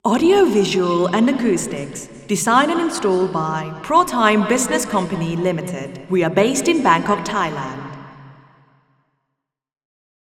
Not surprisingly, this placement produces some audible flutter echoes, but the improvement in SI is striking.
Receiver 01     STI = 0.83
RIR_MFA_W_A1_01_Female_Anechoic_Speech_MONO.wav